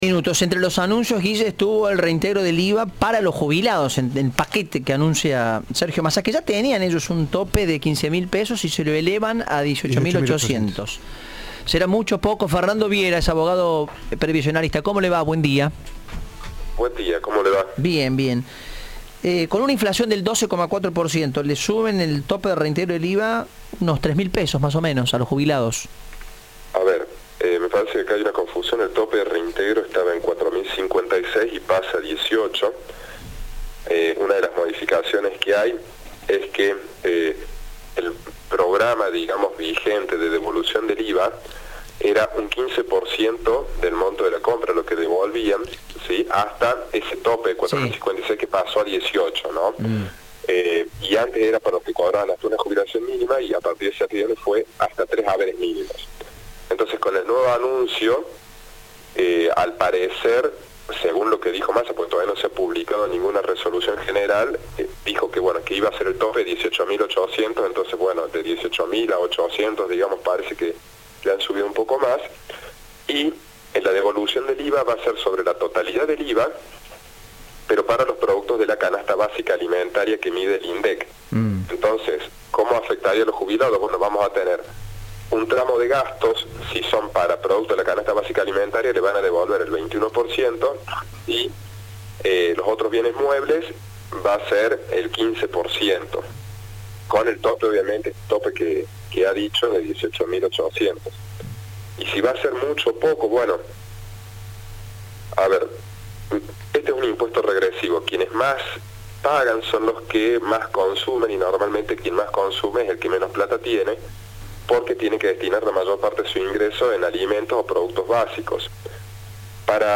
habló en Cadena 3 tras el anuncio de Sergio Massa.